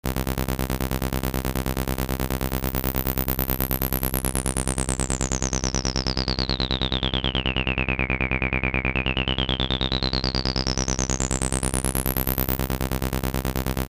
Przykłady dźwiękowe uzyskane w wyniku syntezy subtraktywnej oraz ich reprezentacje widmowe:
Ten sam sygnał filtrowany przy stałej dobroci filtru i zmieniającej się częstotliwości odcięcia ma postać:
Dźwięk uzyskany przy stałej dobroci filtru i zmieniającej się częstotliwości odcięcia